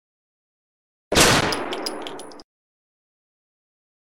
Sound Buttons: Sound Buttons View : Gun Shot
m9-pistol-sound-effect.mp3